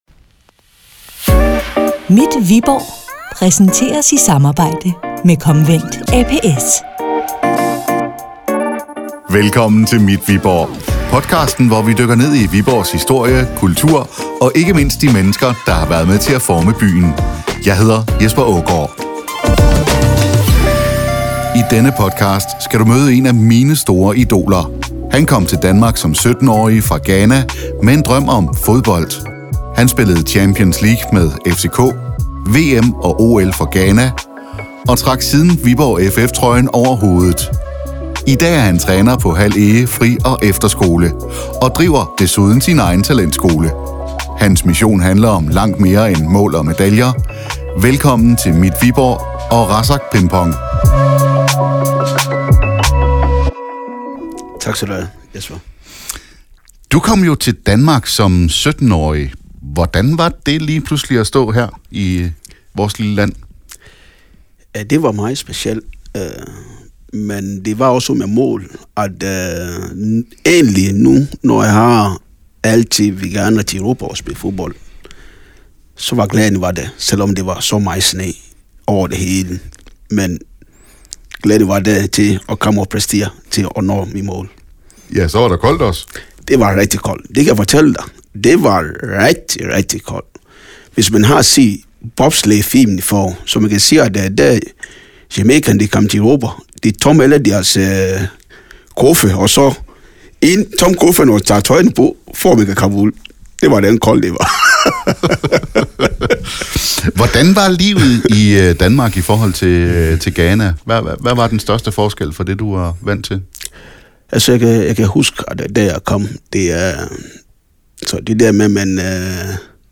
En samtale om passion, identitet og fodboldens kraft.